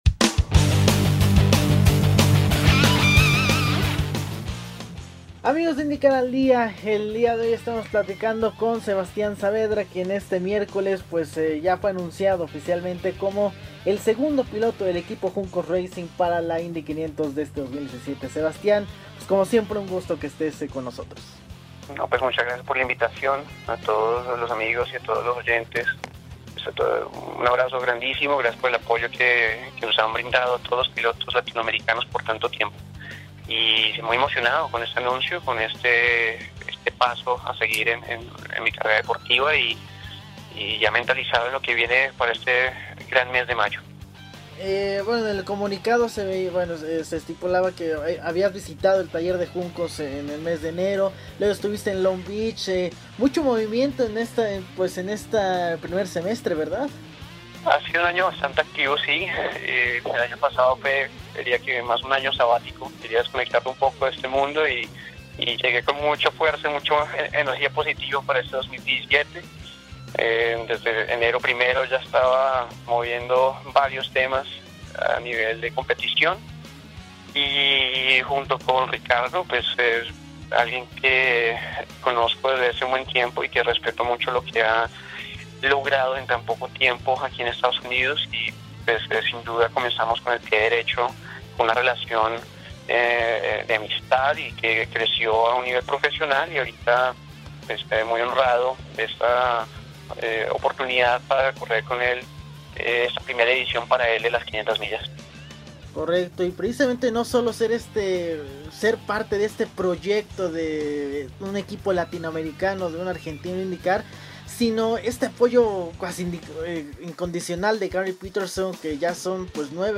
«IndyCar al Día» tuvo la oportunidad de conversar con el bogotano; te invitamos a escuchar sus reacciones iniciales tras el anuncio de este miércoles.